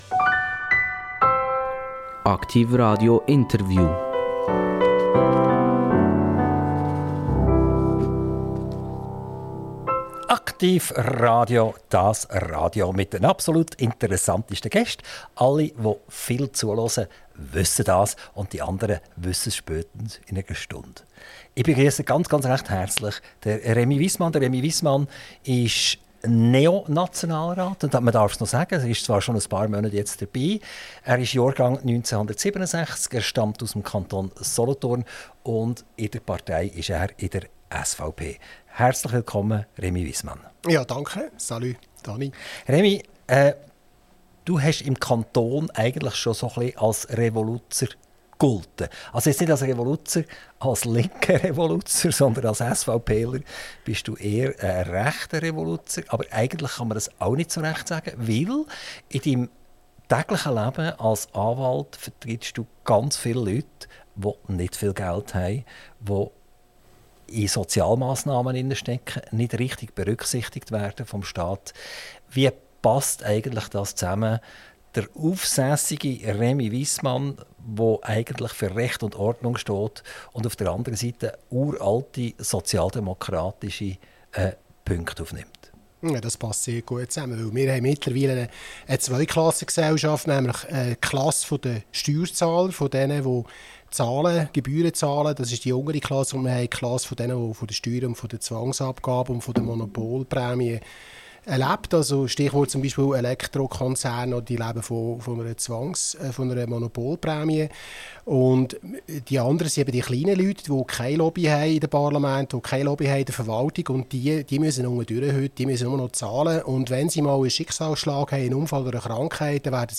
INTERVIEW - Rémy Wyssmann - 20.03.2024